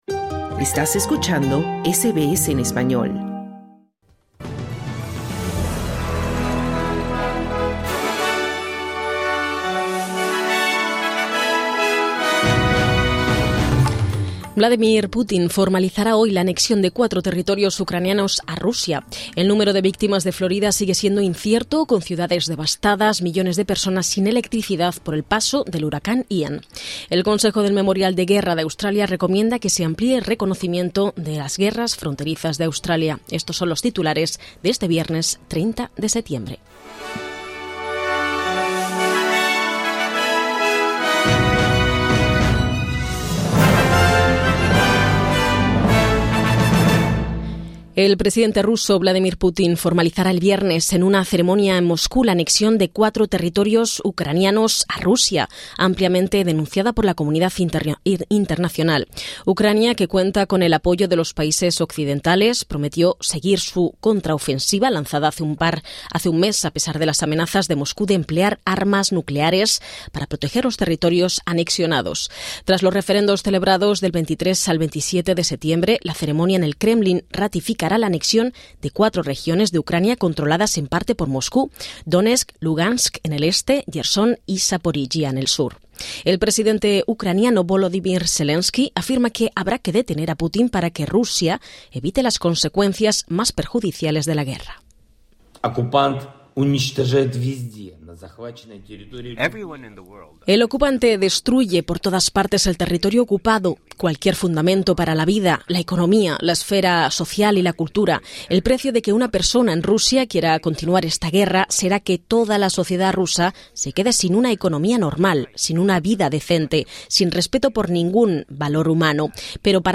Noticias SBS Spanish | 30 septiembre 2022